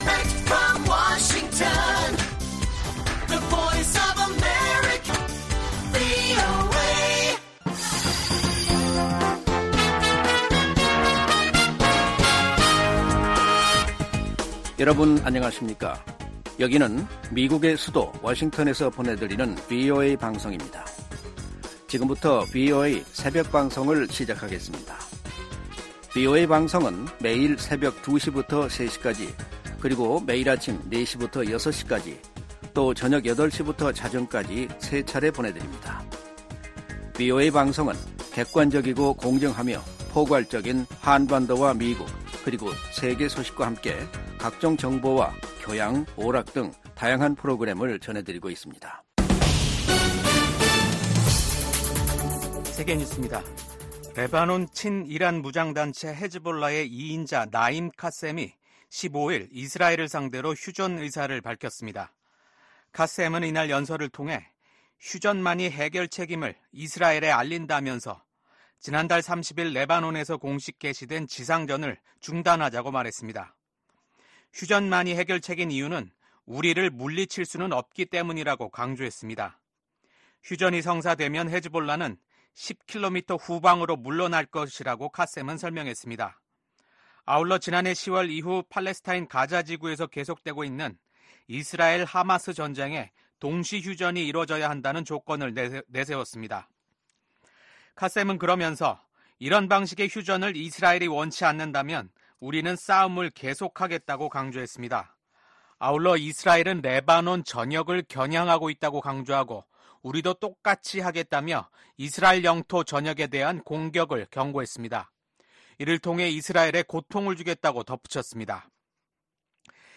VOA 한국어 '출발 뉴스 쇼', 2024년 10월 16일 방송입니다. 북한의 ‘한국 무인기 평양 침투’ 주장으로 한반도 긴장이 고조되고 있는 가운데 북한군이 오늘(15일) 남북을 잇는 도로들을 폭파했습니다. 16일 서울에서 미한일 외교차관협의회가 열립니다.